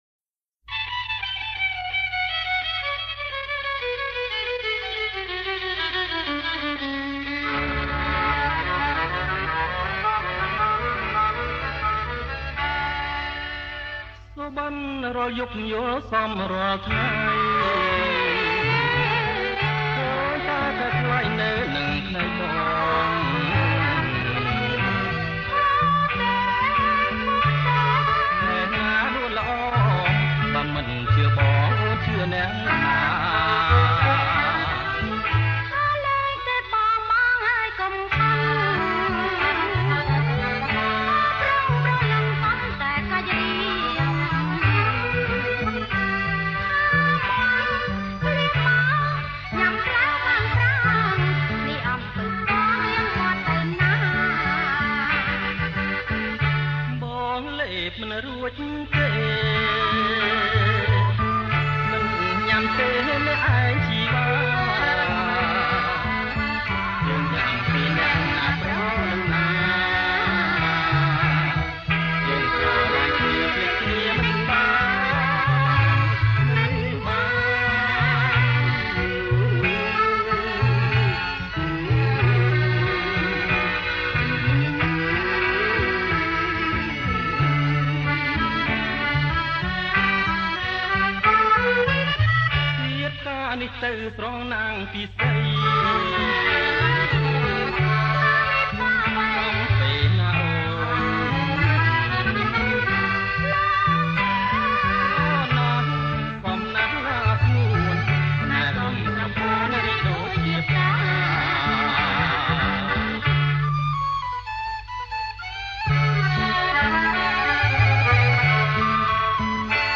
• ប្រគំជាចង្វាក់ Bolero twist